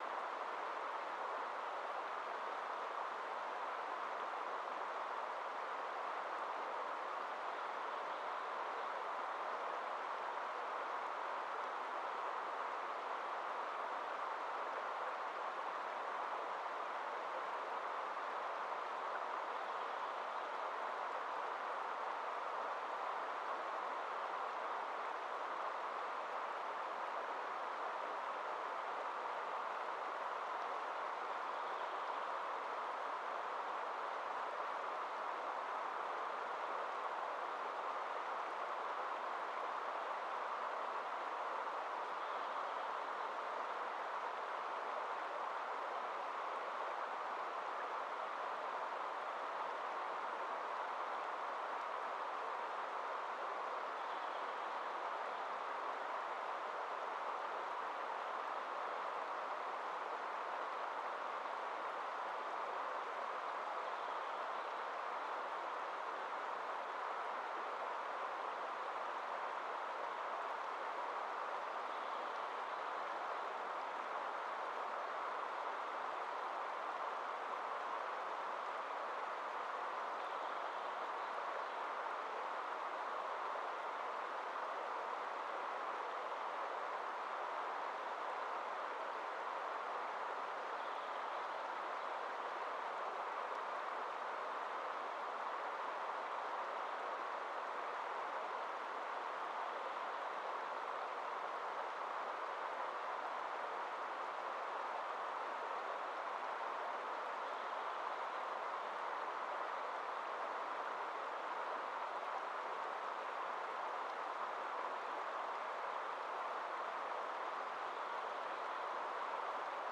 Quellrauschen1000.mp3